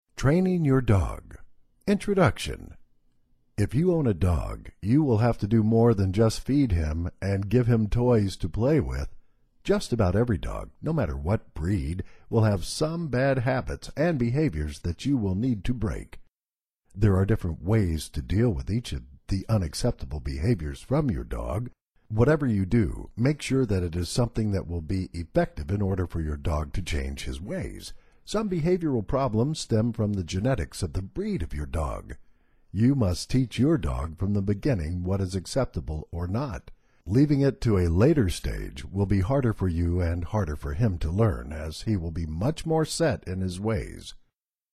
Training Your Dog Audio eBook